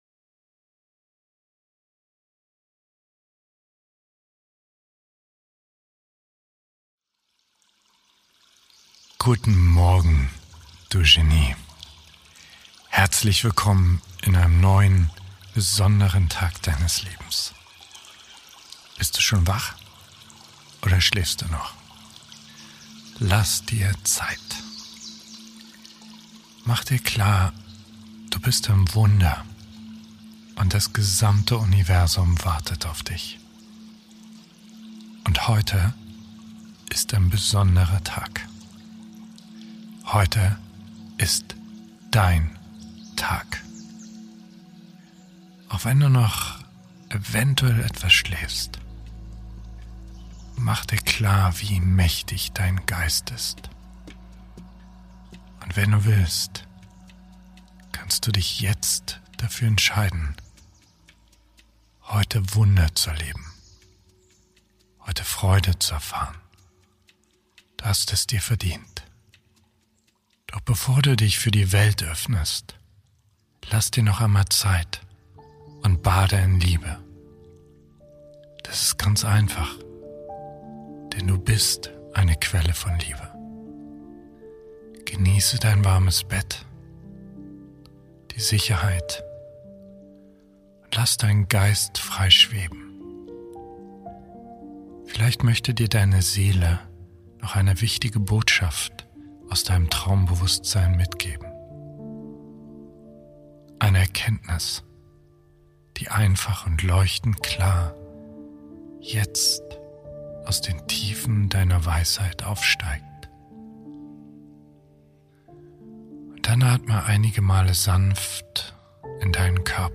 Eine Dosis Selbstliebe am Morgen | Geführte Meditation